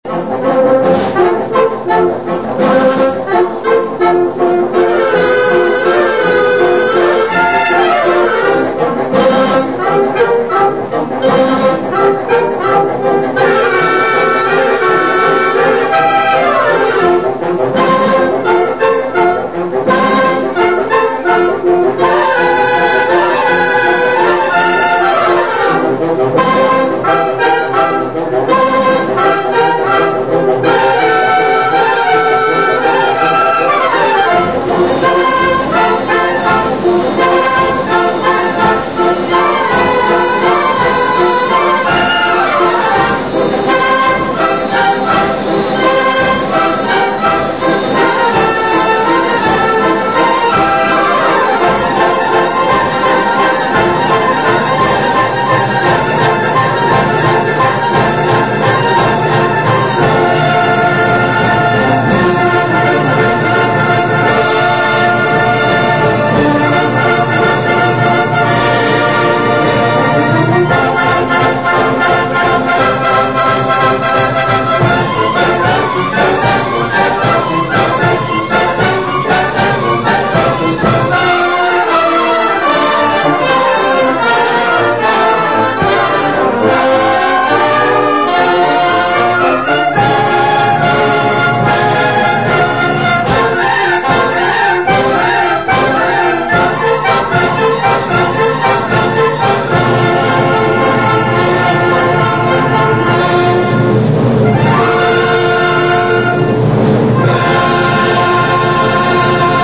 Ascolta Registrazione dal vivo anni 80 Teatro Oriente - Torre del Greco Se non si ascolta subito la musica attendere qualche secondo solo la prima volta.